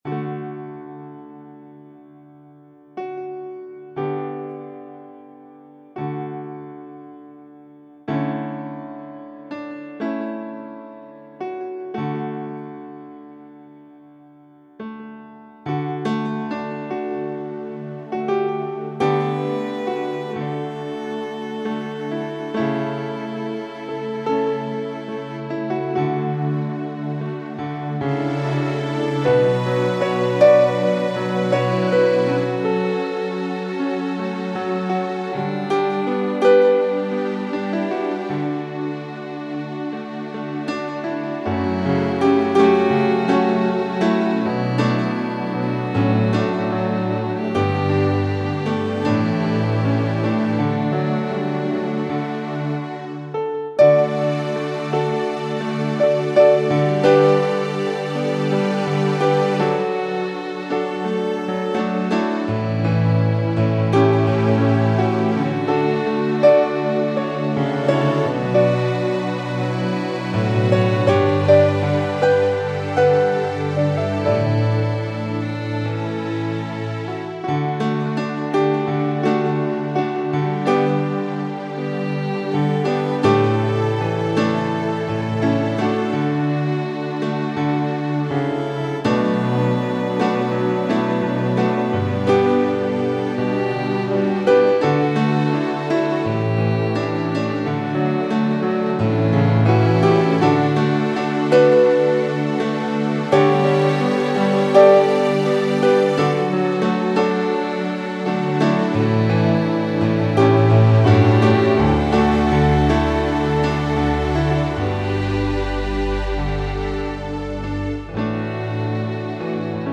Nüüd on siis demona olemas (seda annaks veel tublisti viimistleda) ka [L]
Korg Kronose versioon (10MB, 320kbit MP3).